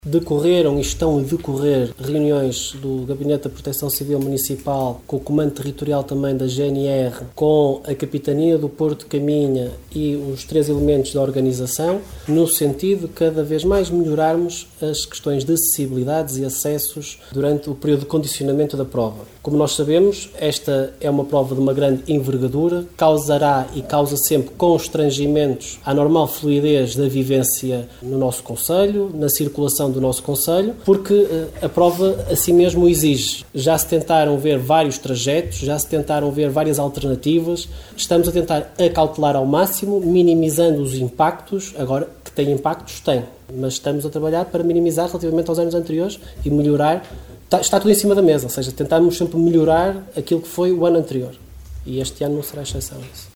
Excertos da última reunião de câmara, no passado dia 7 de Fevereiro, onde foram aprovados os apoios financeiros à Associação Triatlo de Caminha para a realização do Triatlo Longo, Meia Maratona e Corrida de São Silvestre, cujos percursos e condições se mantêm, segundo os protocolos, idênticos às edições dos anos anteriores.